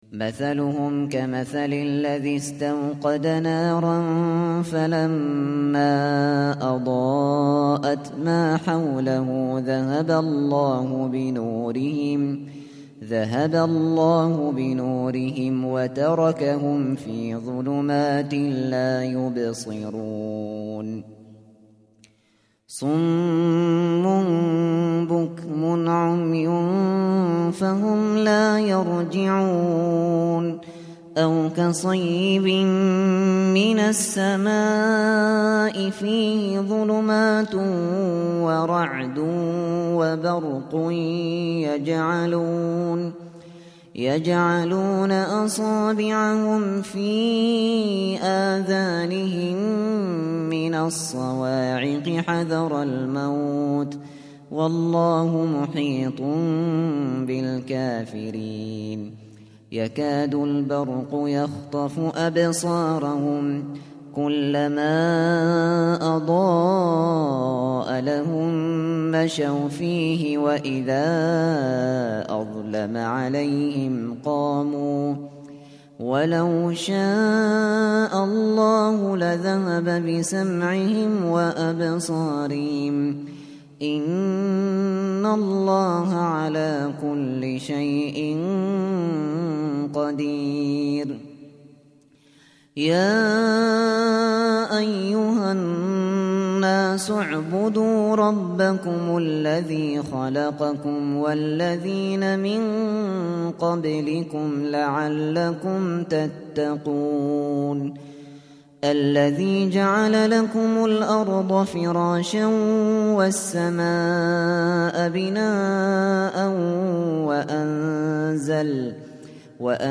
Kur'ân dinlemeye başlamak için bir Hafız seçiniz.